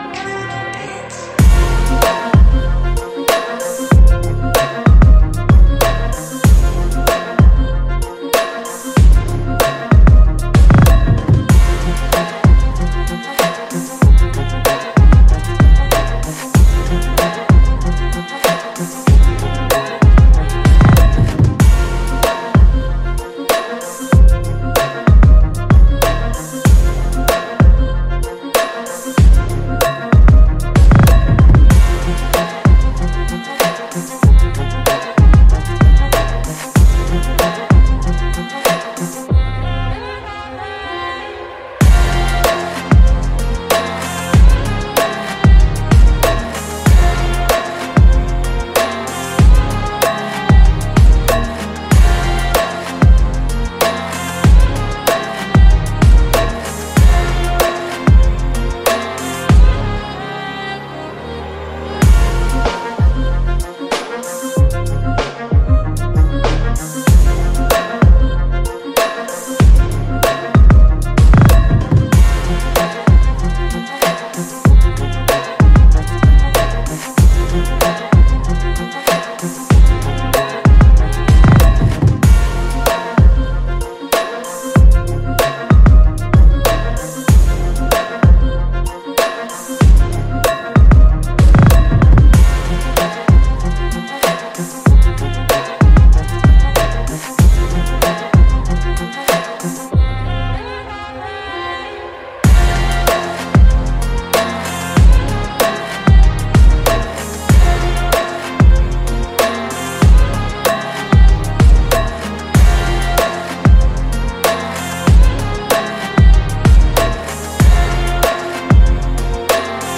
Hard Violin Rap Beat
Hard-Violin-Rap-Beat.mp3